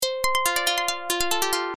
标签： 140 bpm Ethnic Loops Synth Loops 295.50 KB wav Key : Unknown
声道立体声